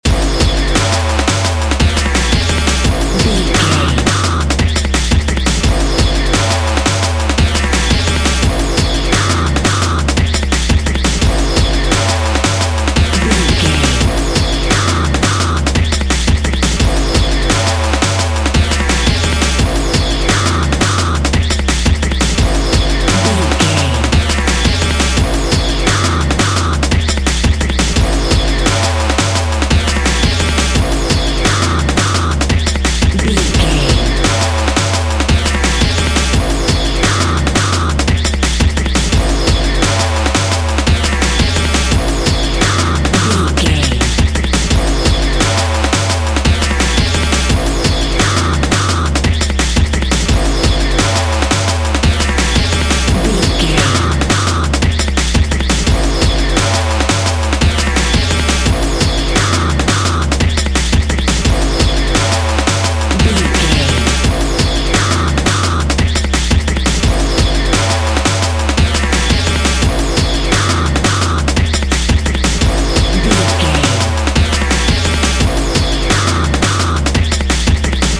Ionian/Major
Fast
futuristic
hypnotic
industrial
frantic
aggressive
dark
drum machine
synthesiser
break beat
electronic
sub bass
synth lead